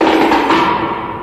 FX (GHOST TRAIN).wav